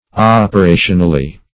operationally.mp3